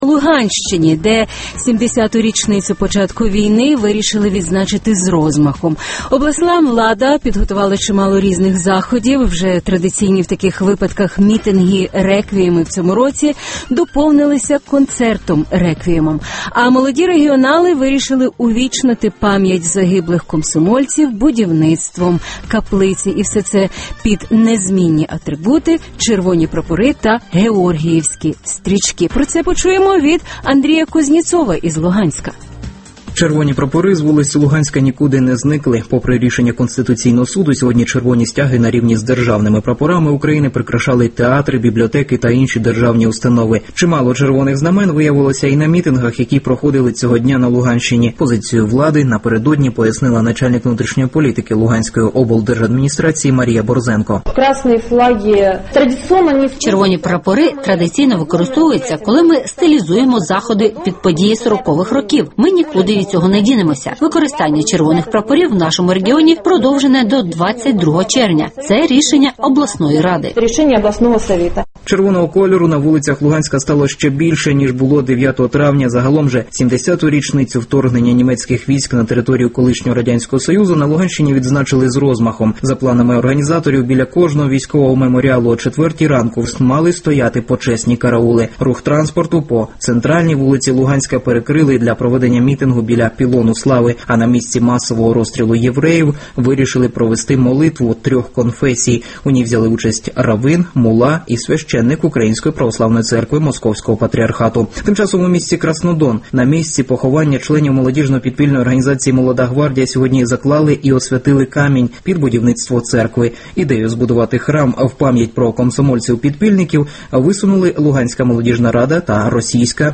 22 червня в Луганську співали